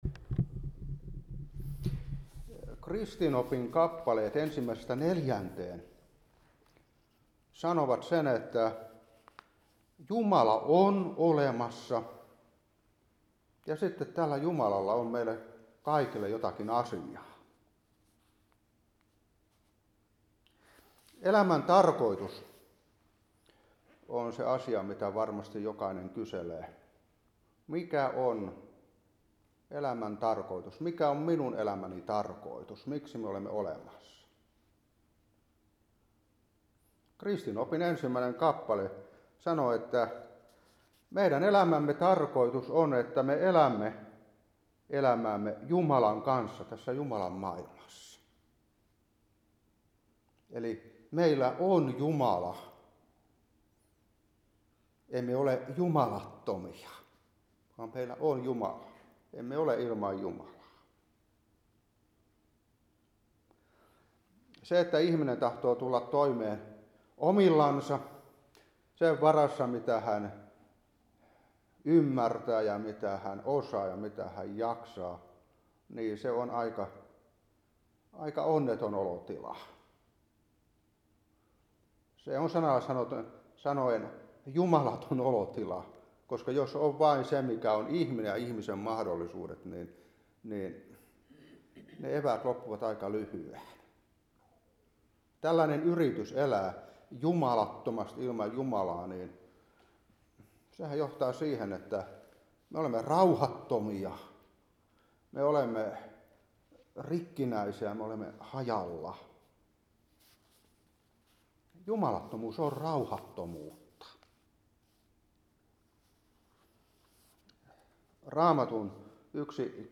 Katekismusopetus 2024-1. Kristinoppi 1-4.